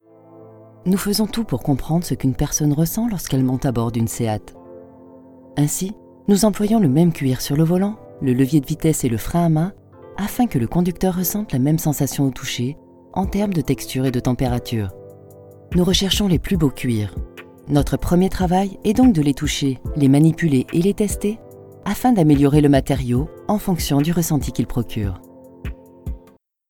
Audioguide - I have a broadcast-quality studio: Neumann TLM 103 microphone, M-Audio Fast Track Ultra sound card, Vovox cables, acoustic cab.
Sprechprobe: Industrie (Muttersprache):